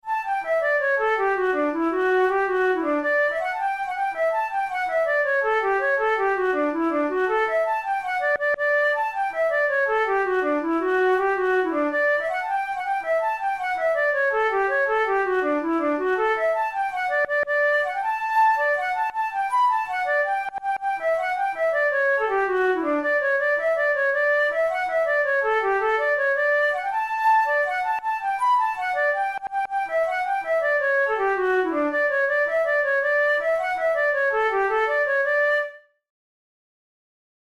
InstrumentationFlute solo
KeyD major
Time signature6/8
Tempo108 BPM
Jigs, Traditional/Folk
Traditional Irish jig